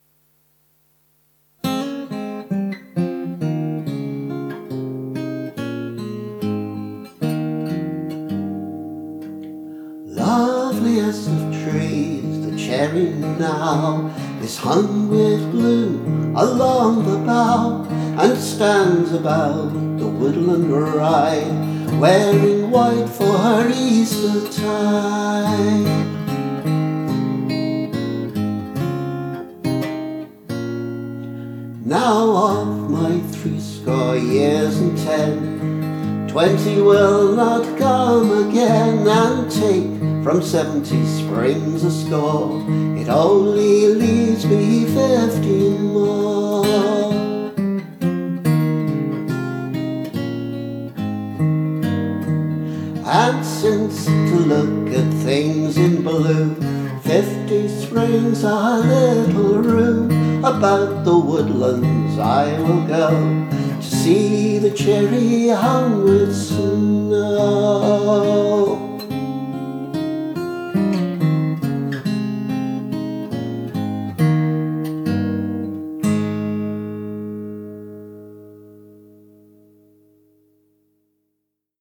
It’s more or less the A tune from the reel ‘The Rose Tree’.